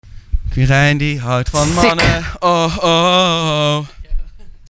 blooper-3.mp3